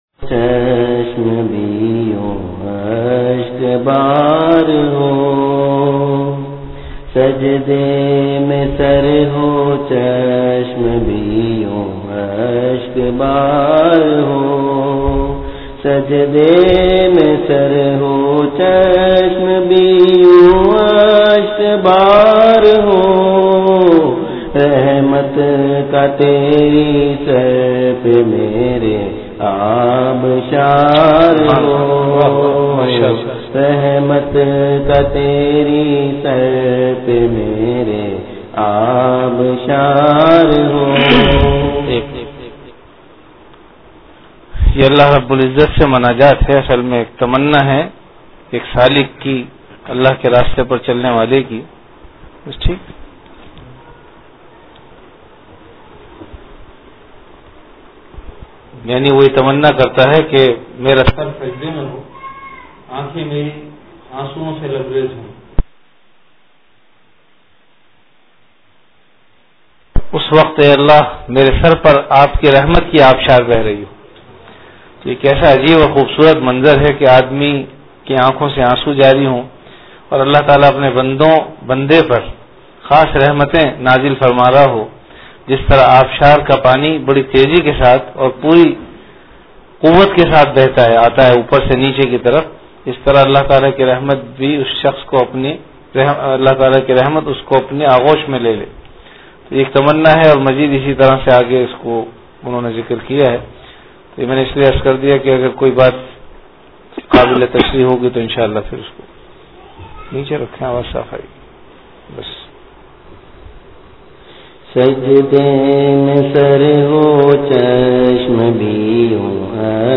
An Islamic audio bayan